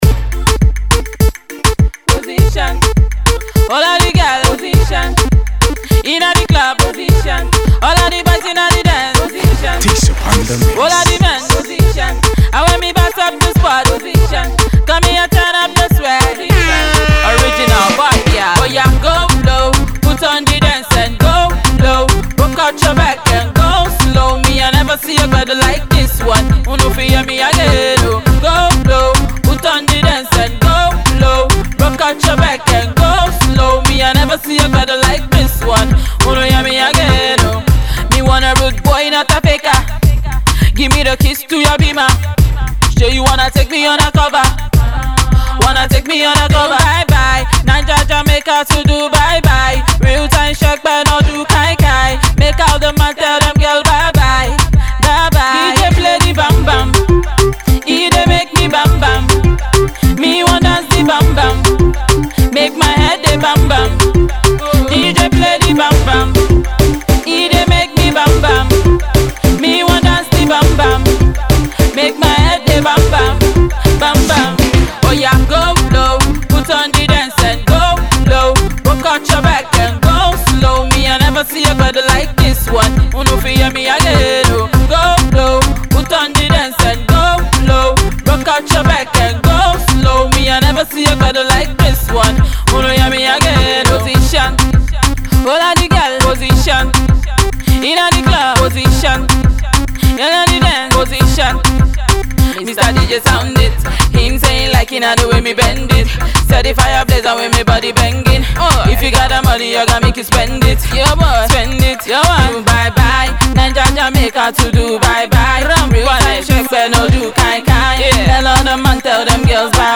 Pop
Ragga/Hip-Hop